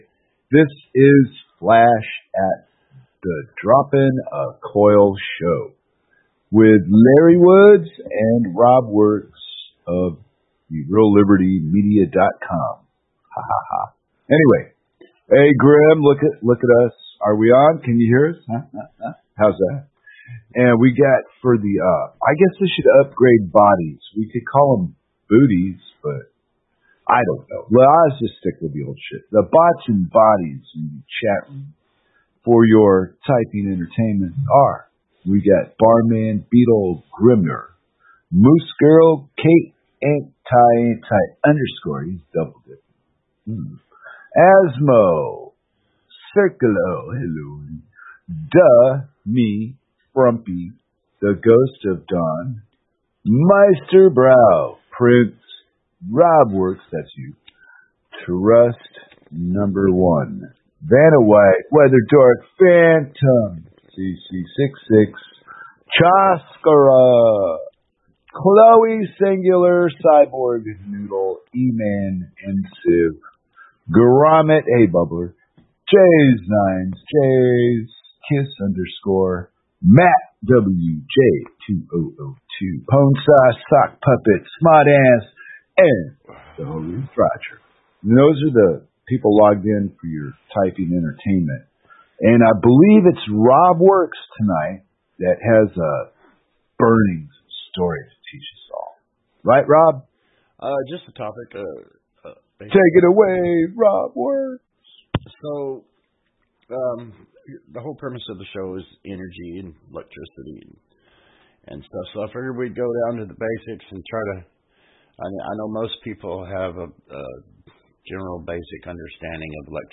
Album Dropping a Coil Genre Talk